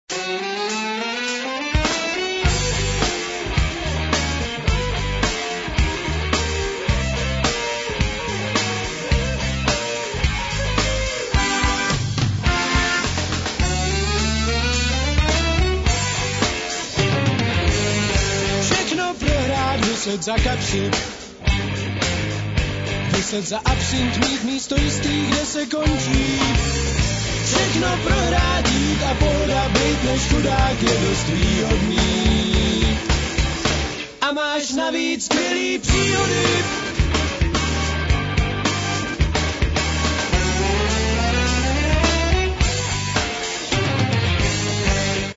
kultovní kapela české alternativní rockové scény
sax,klarinet
sax,fl